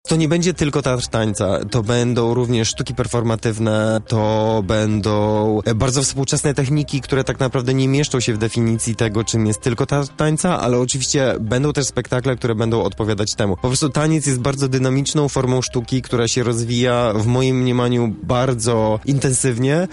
Poniżej link do całej rozmowy